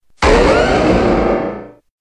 Grito de Mega-Garchomp.ogg
Grito_de_Mega-Garchomp.ogg